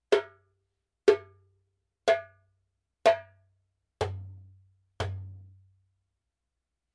Djembe made in Mali
Wood : Gueni ゲニ (バラフォンウッド)
トンの胴鳴り、カンのシャープネス、パワー感もあって、ダイナミックな表現が出来る楽器です。
ジャンベ音